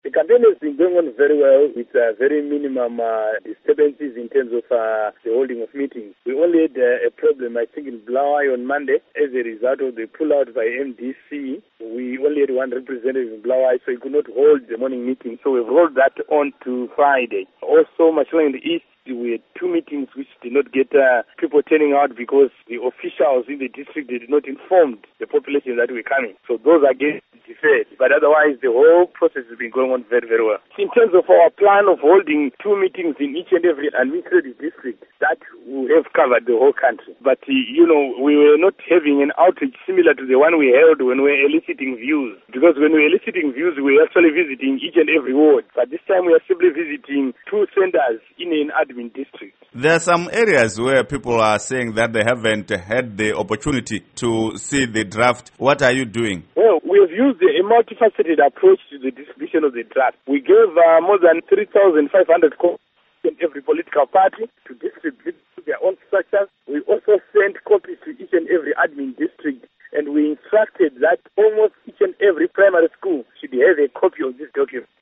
Interview With Munyaradzi Paul Mangwana